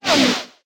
fastCarNear.ogg